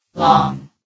sound / vox_fem / long.ogg
CitadelStationBot df15bbe0f0 [MIRROR] New & Fixed AI VOX Sound Files ( #6003 ) ...
long.ogg